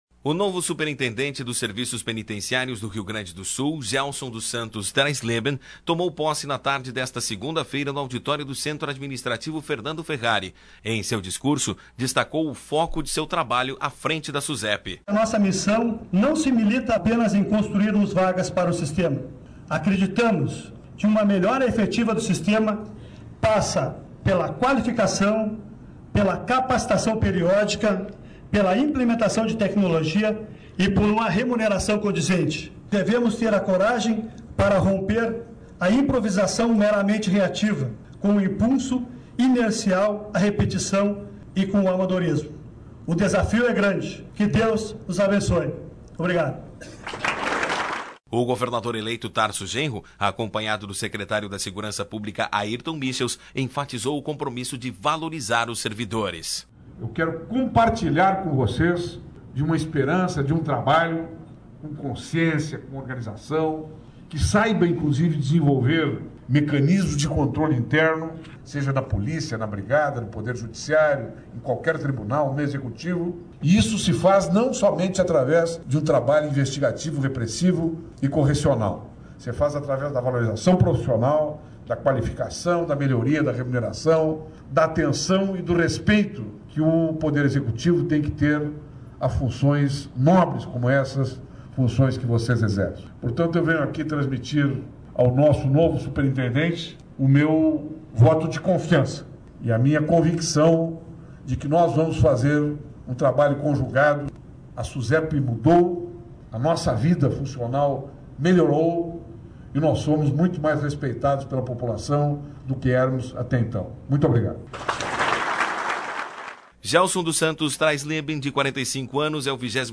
Tarso Genro valoriza servidores da Susepe durante a posse de novo superintendente